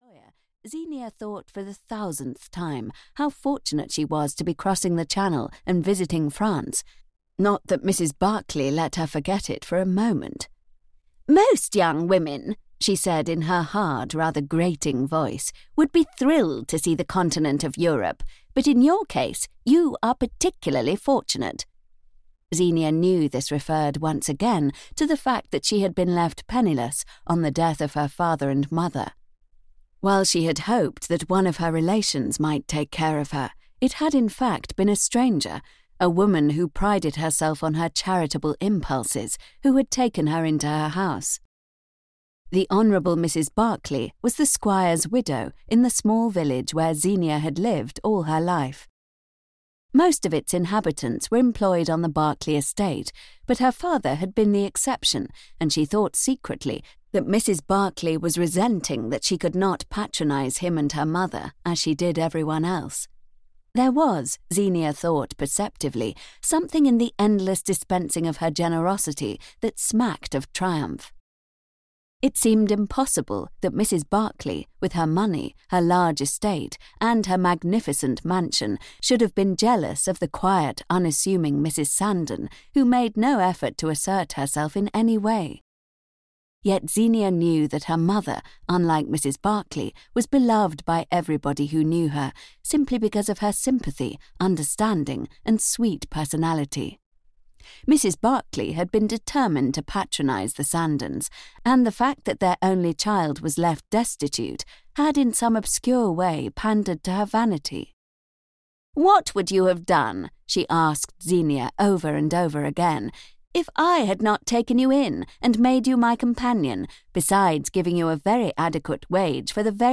Love Leaves at Midnight (EN) audiokniha
Ukázka z knihy